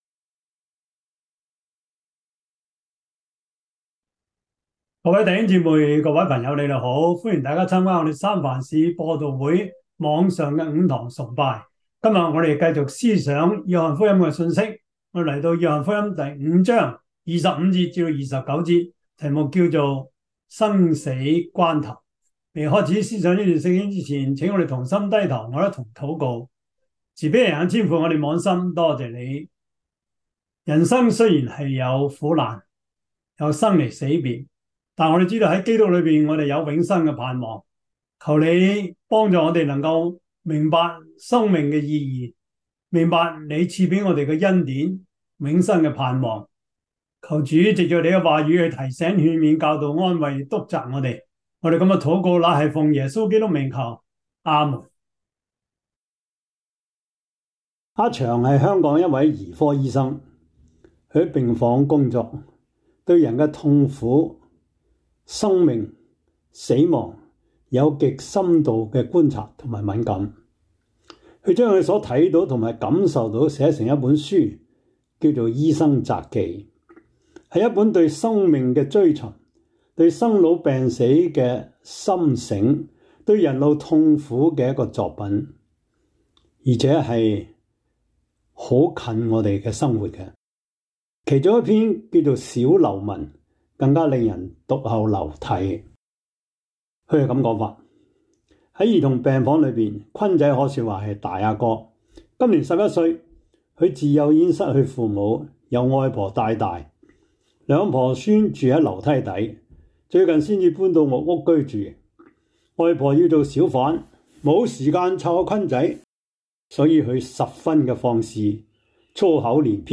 Topics: 主日證道 « 愛在主裡面 第三十三課: 慈殺 »